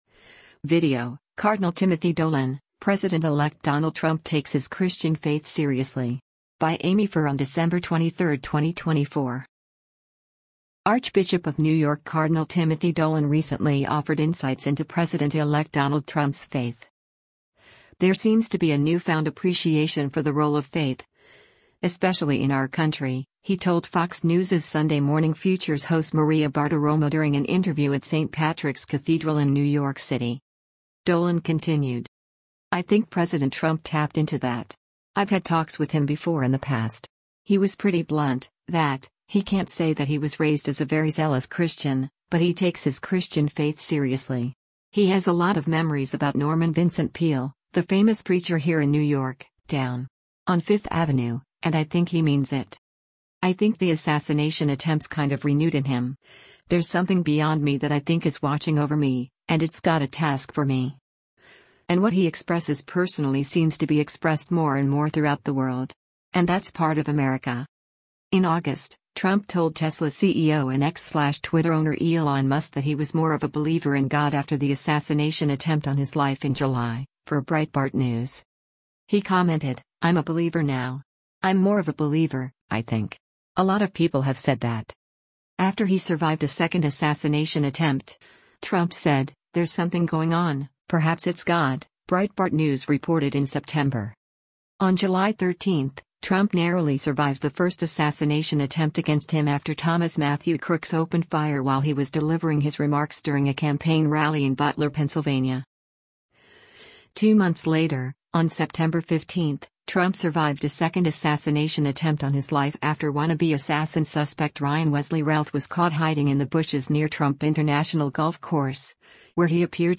“There seems to be a newfound appreciation for the role of faith, especially in our country,” he told Fox News’s Sunday Morning Futures host Maria Bartiromo during an interview at St. Patrick’s Cathedral in New York City.